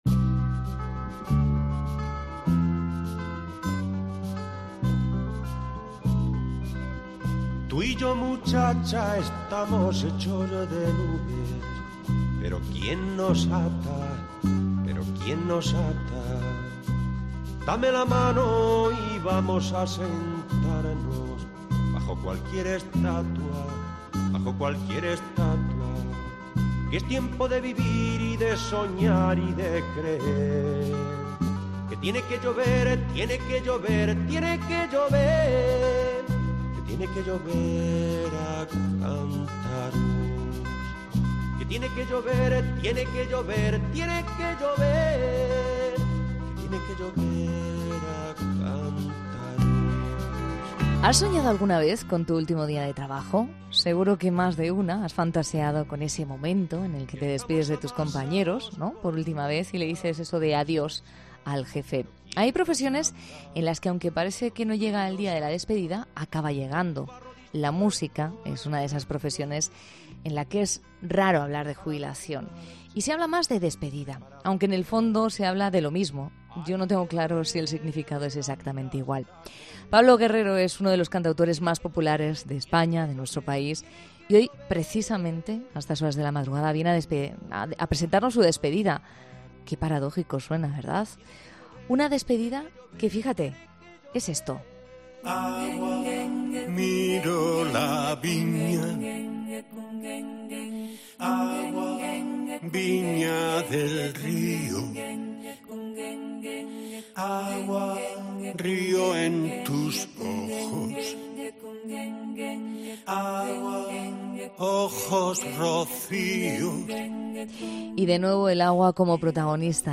En La Noche de COPE contamos con la visita de una de las miradas más icónicas de la música de nuestro país.